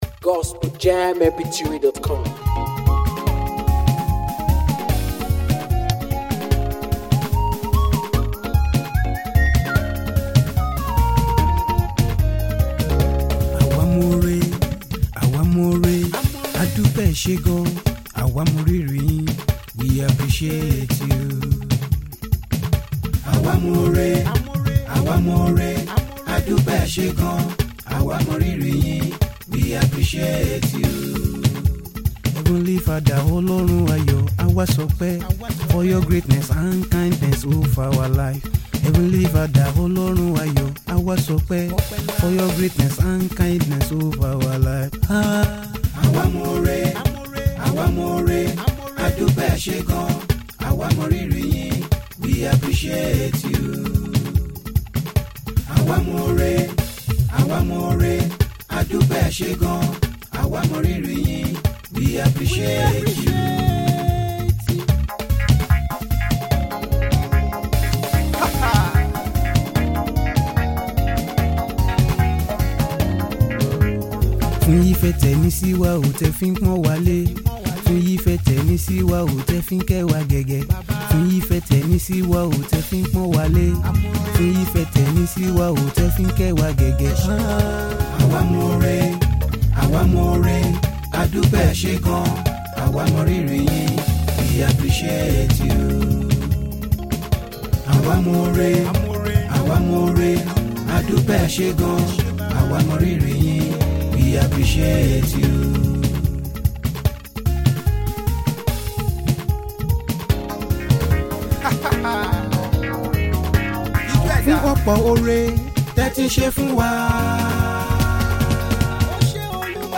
soulful and spirit-lifting gospel song
With heartfelt vocals, rich instrumentals